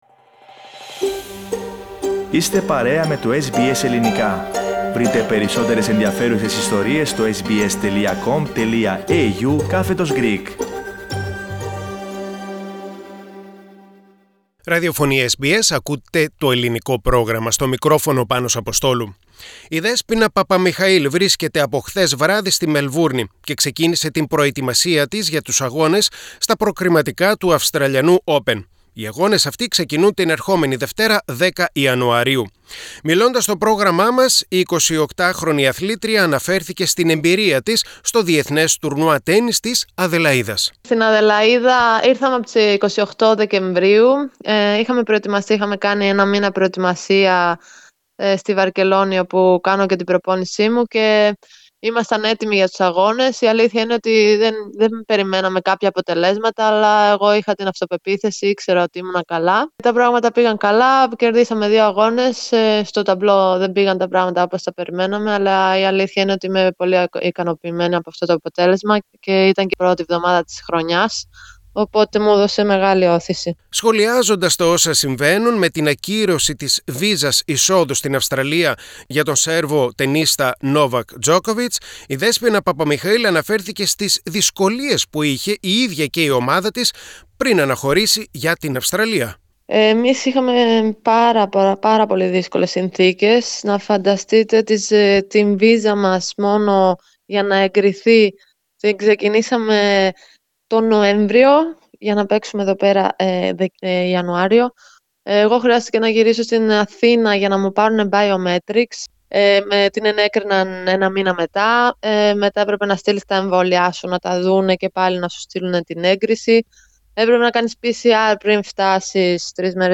Στην Μελβούρνη βρίσκεται από χθες η Ελληνίδα τενίστρια Δέσποινα Παπαμιχαήλ η οποία ξεκίνησε την προετοιμασία της για τα προκριματικά του Αυστραλιανού Όπεν και μίλησε στο SBS Greek.